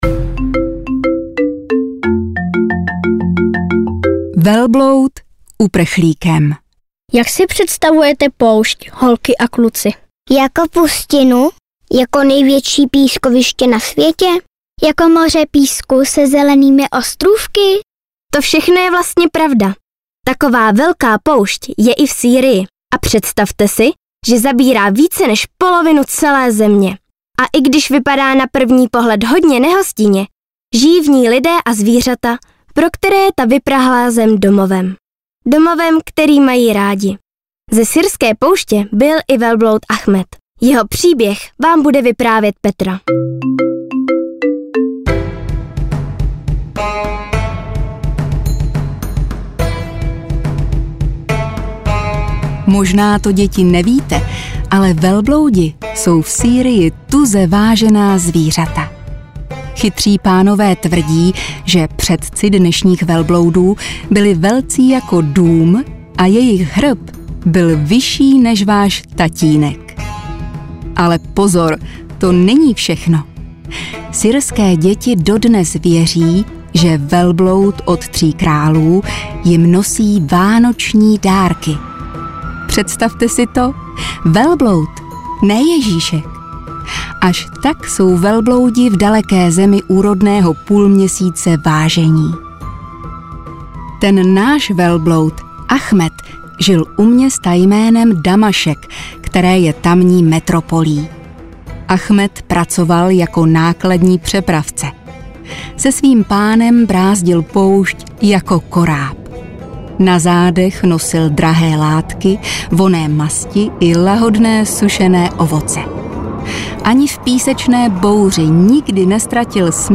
Do Sýrie nejen za pohádkou audiokniha
Ukázka z knihy
Jedinečná audiokniha plná pohádek, říkanek a písniček v syrském duchu.